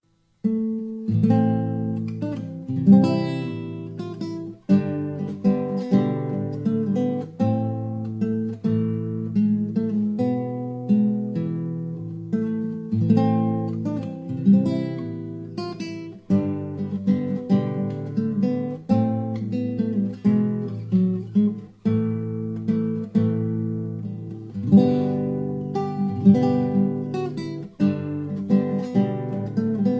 Traditional Polish Christmas Carols on classical guitar
(No Singing).